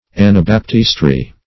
Search Result for " anabaptistry" : The Collaborative International Dictionary of English v.0.48: Anabaptistry \An`a*bap"tist*ry\, n. The doctrine, system, or practice, of Anabaptists.